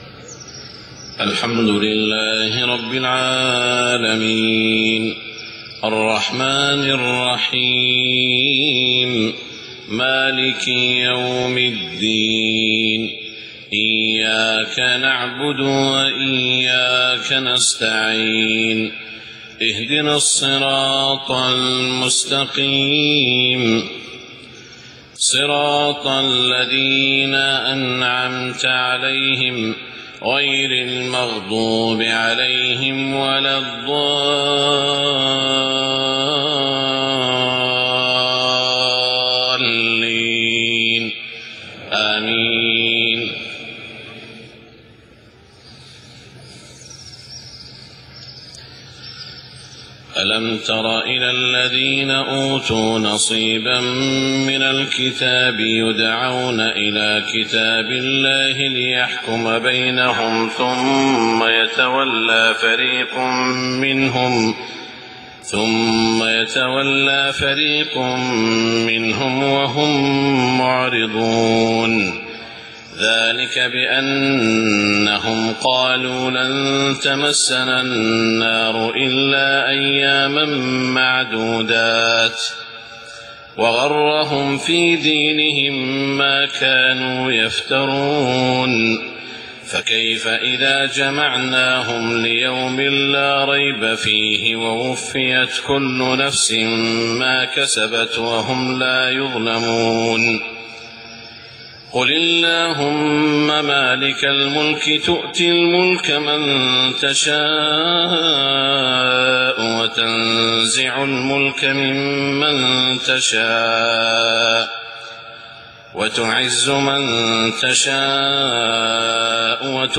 صلاة الفجر 8-4-1430هـ من سورة آل عمران > 1430 🕋 > الفروض - تلاوات الحرمين